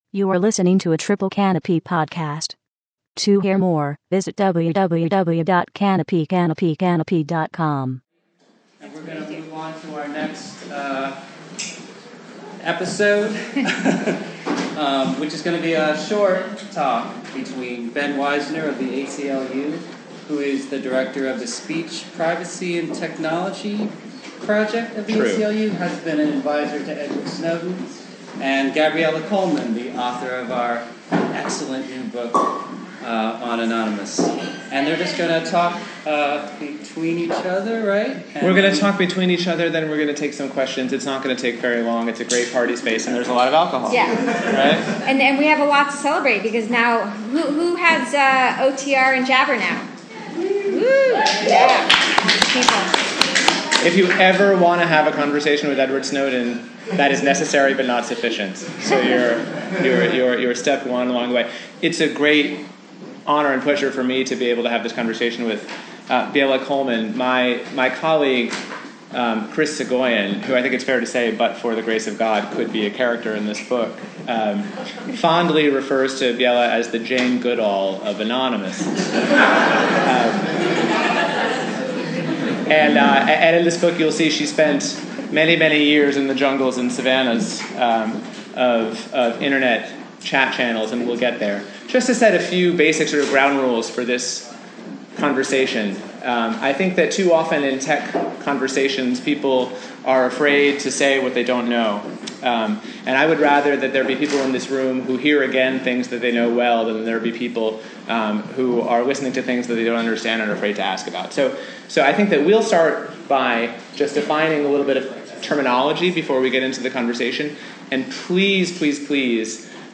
An encryption workshop and a conversation about online surveillance, privacy, and resistance.